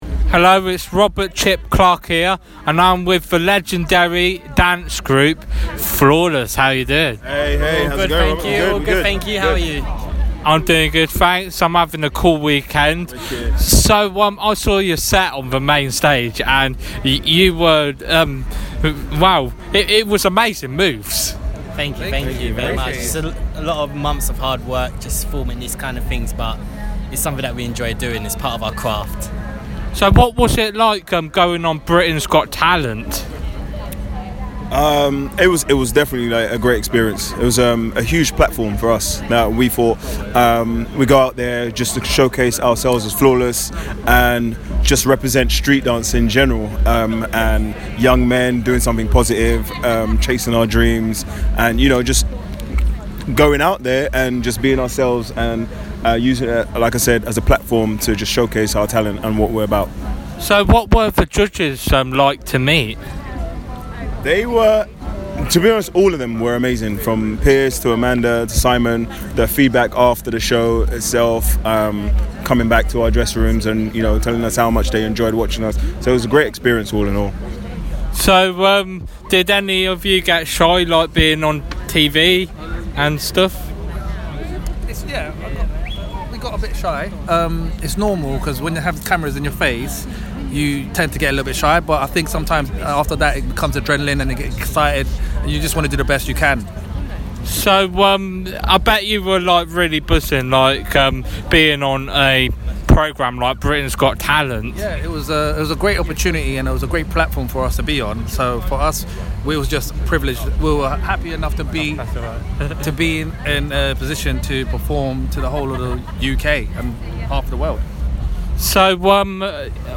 Flawless From BGT Interview 2019